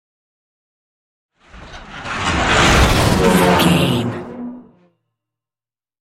Sci fi vehicle whoosh large
Sound Effects
dark
futuristic
whoosh